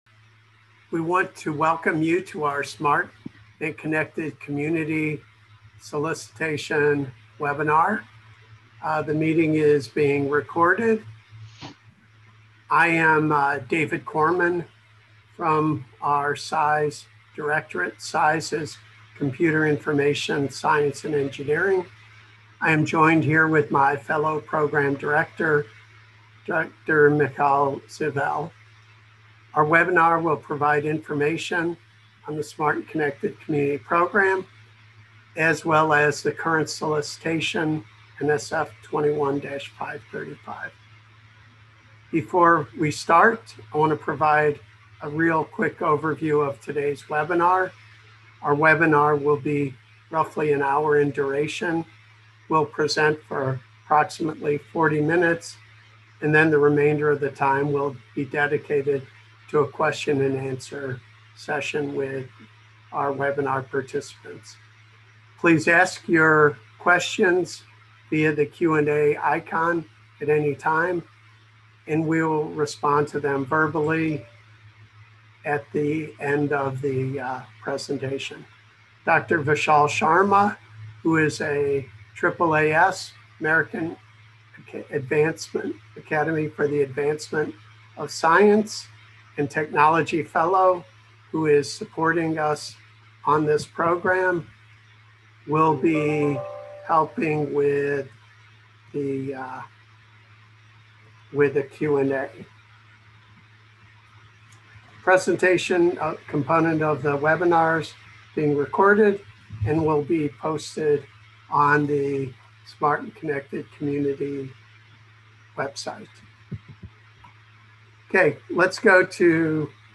Smart and Connected Communities (NSF 21-535) Program Webinar